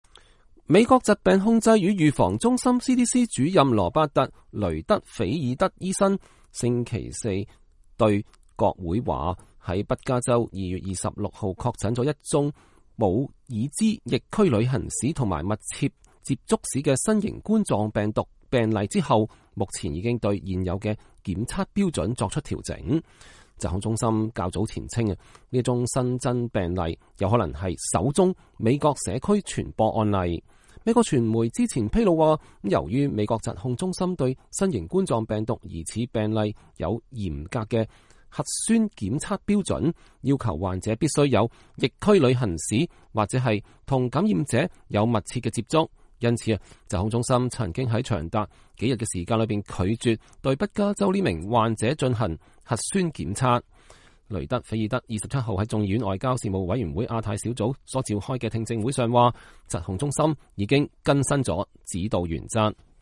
美國疾控中心主任國會作證：已改變檢測標準採取嚴格遏制疫情策略
雷德菲爾德27日在眾議院外交事務委員會亞太小組所召開的聽證會上說，疾控中心已經更新了指導原則。